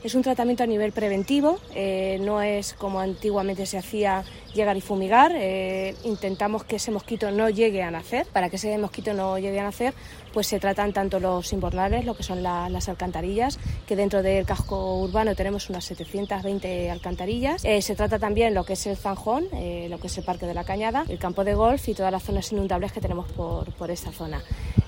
concejala_mambte.mp3